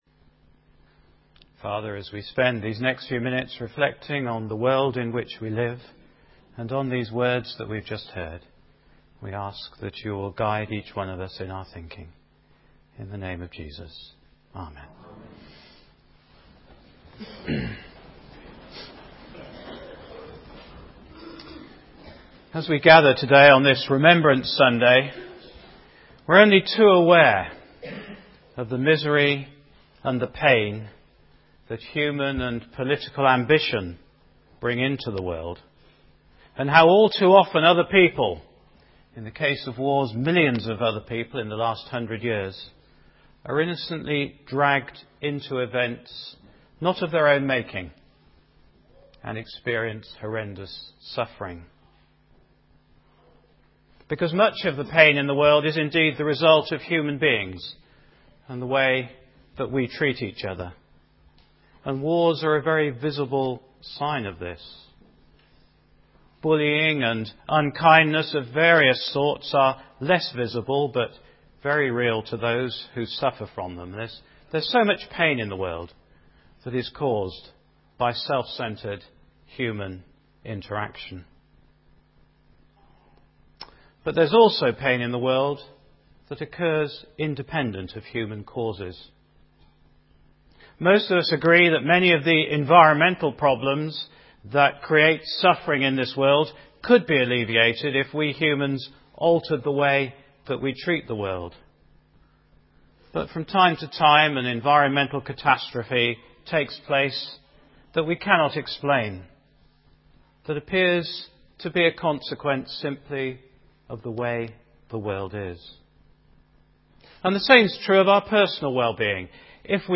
This series of sermons follows the discussions in the book to help look at aspects of Jesus’ Ministry which perhaps were not clear to us.